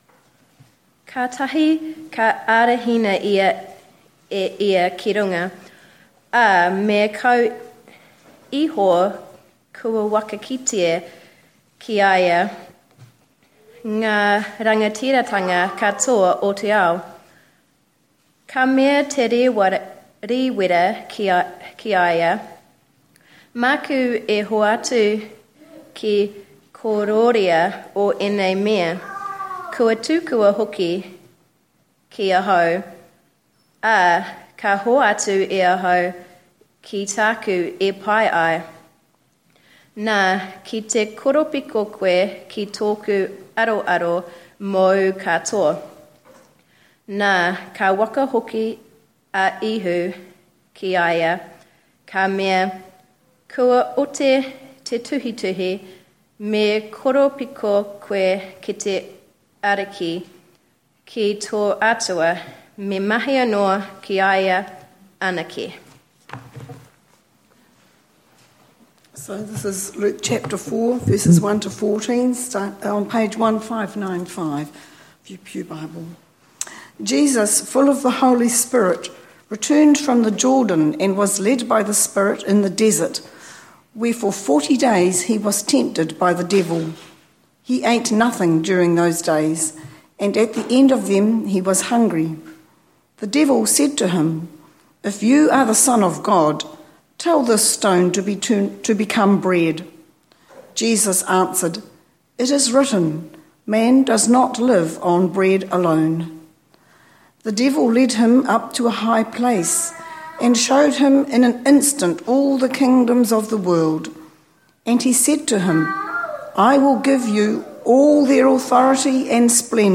Readings Luke 4:1-14 Revelation 13